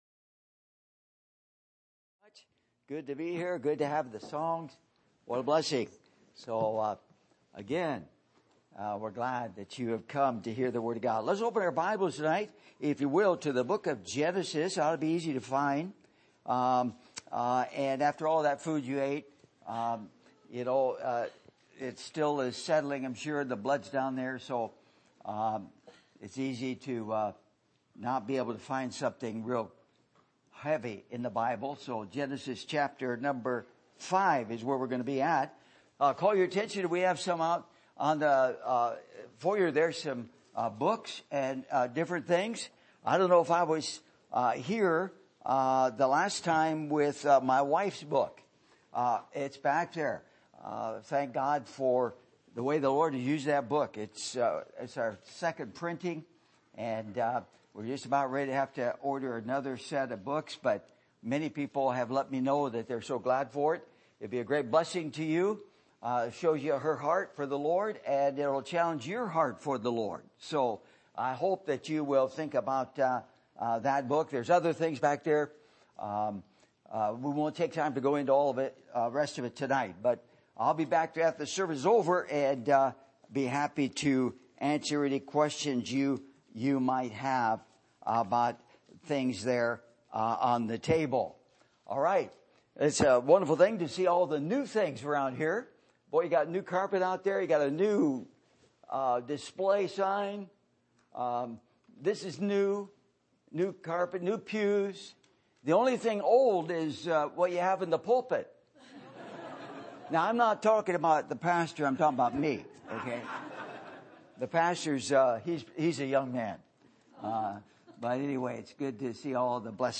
Passage: Genesis 5:21 Service Type: Sunday Evening